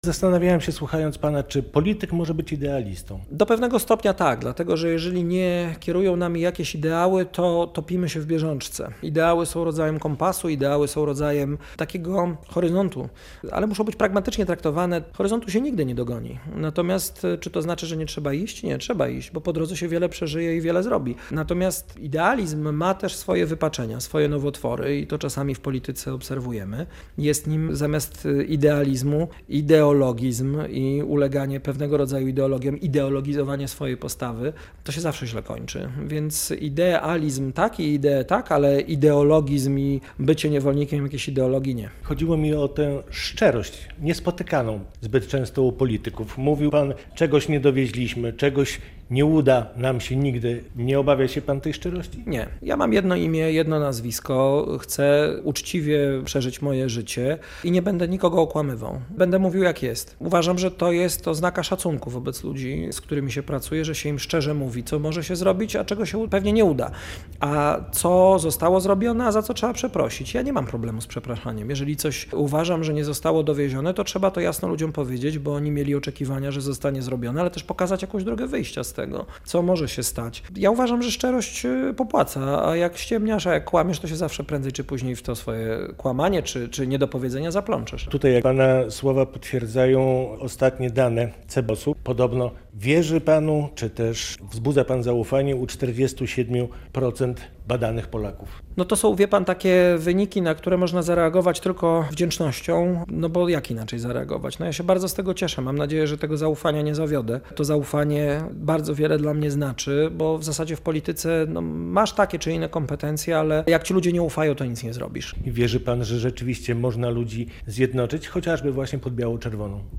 Szymon Hołownia - ludzie najęli 15 października ekipę remontową, ona powinna już remontować [rozmowa]